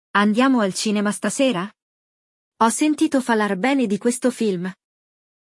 Neste episódio do nosso podcast no Spotify, você vai ouvir um diálogo entre duas pessoas conversando sobre o filme que vão assistir.
O diálogo é claro e voltado para quem quer aprender de forma acessível.